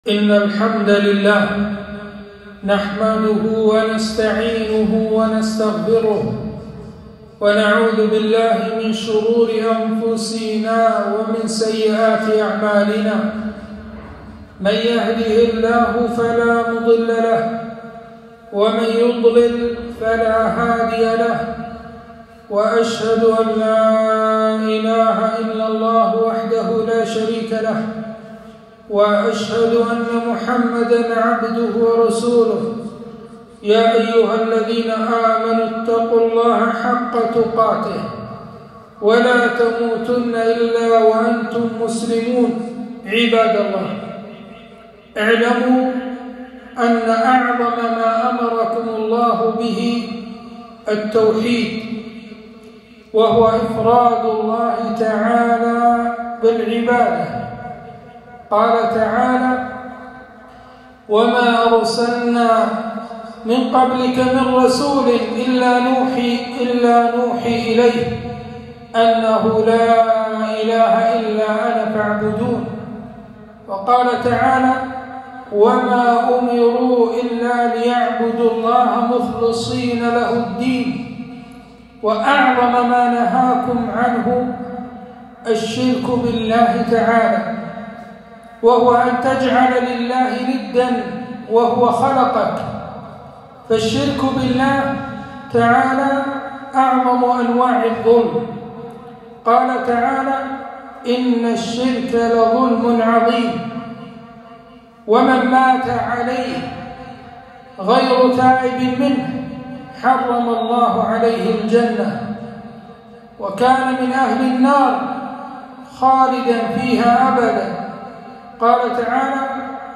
خطبة - التحذير من مظاهر الشرك